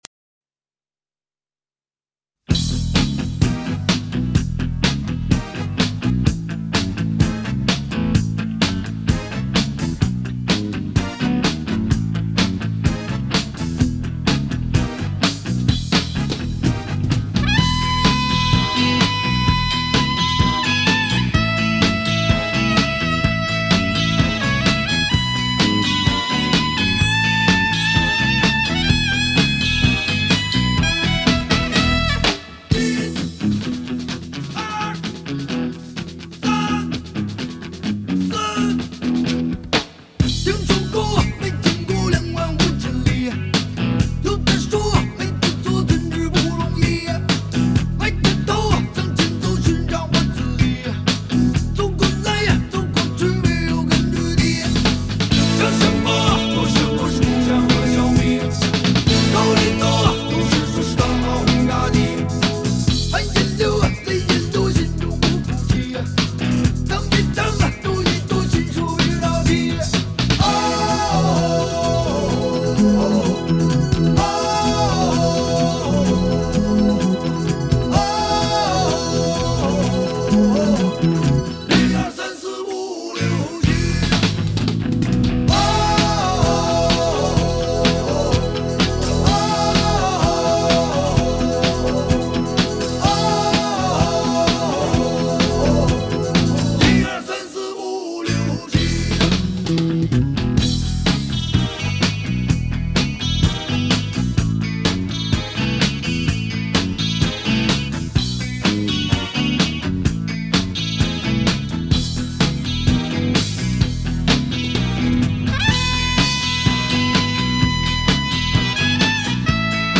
类别: 摇滚